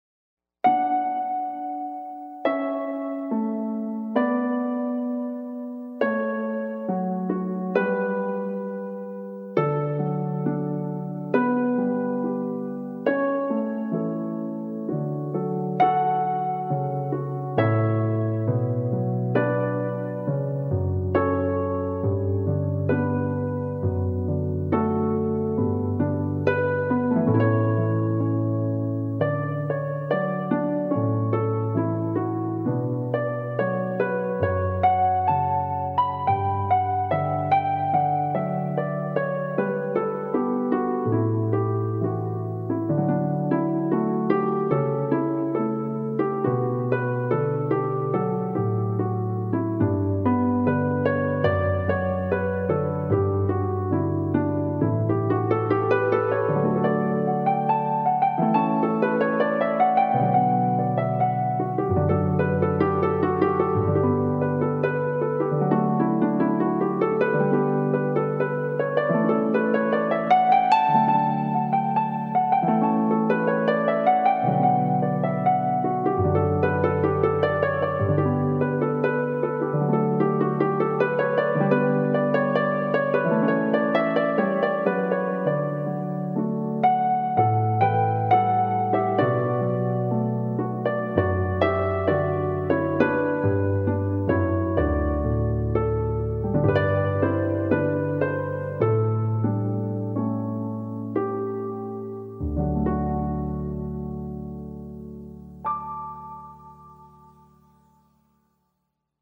Solo Piano/Keyboard
harp/classical